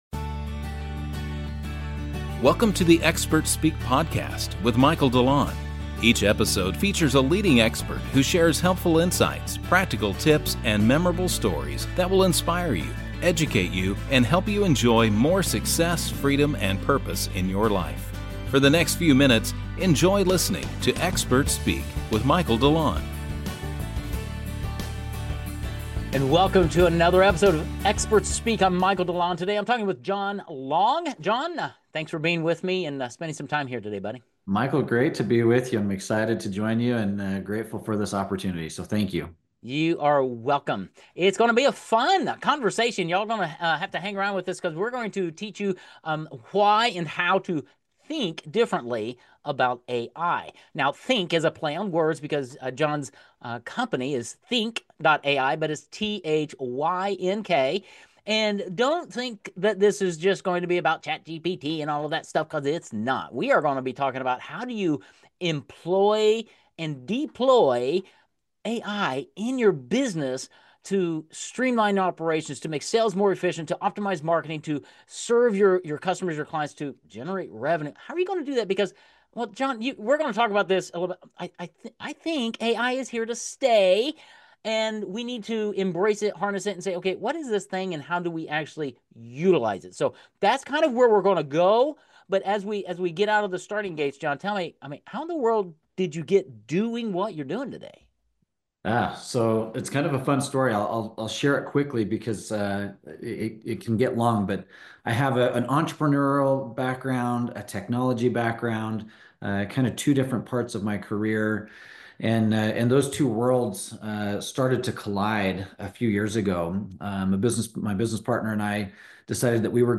Listen to this fun interview